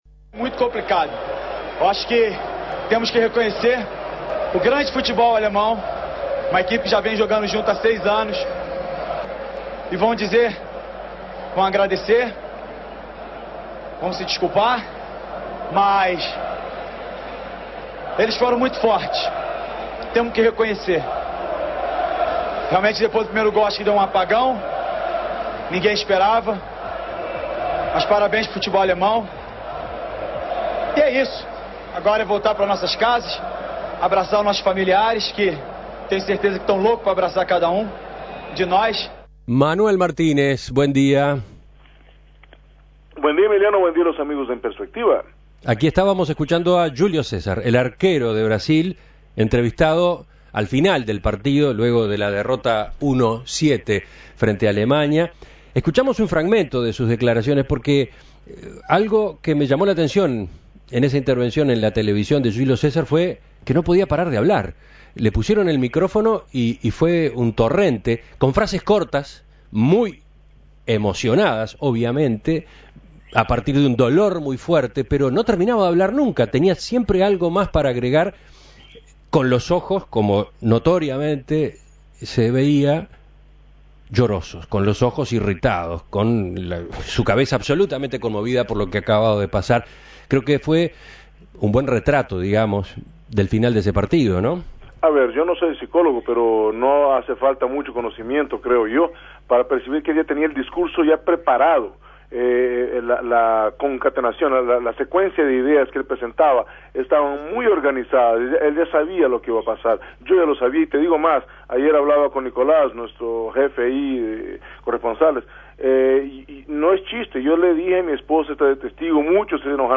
(Audio de las declaraciones del arquero brasileño, Júlio César)